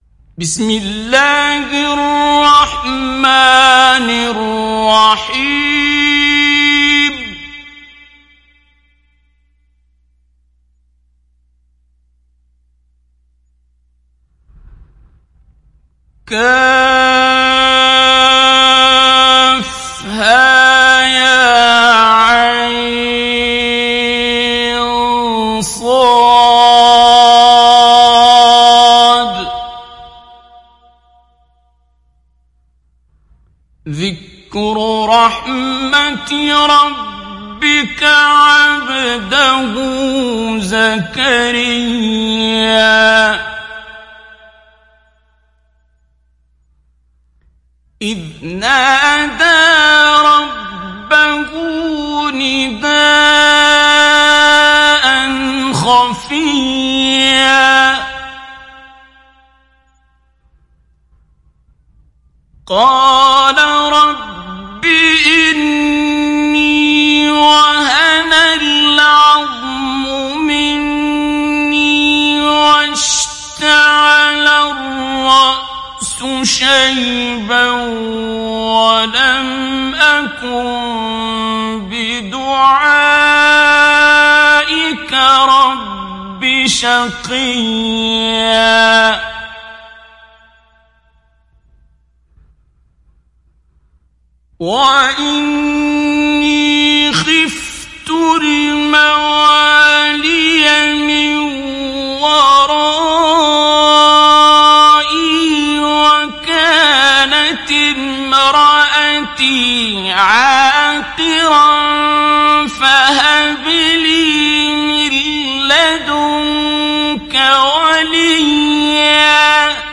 Télécharger Sourate Maryam Abdul Basit Abd Alsamad Mujawwad